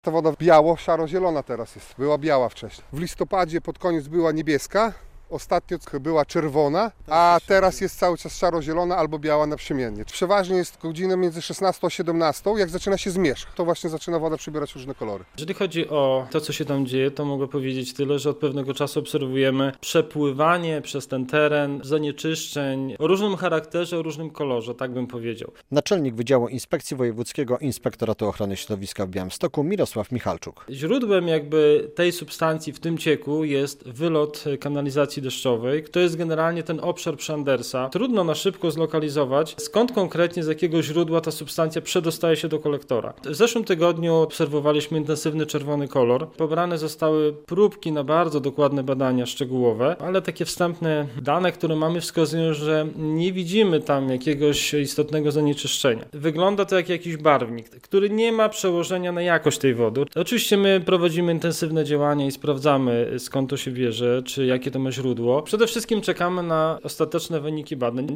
Zanieczyszczony dopływ Białej - relacja